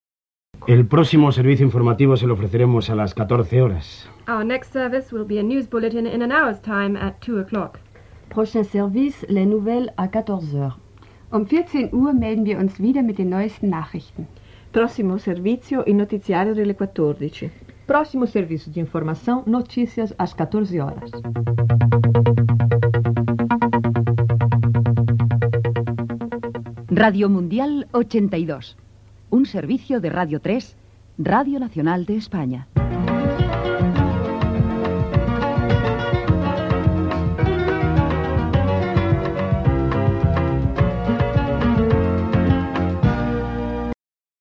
Comiat de l'informatiu en diferents idiomes i identificació.
FM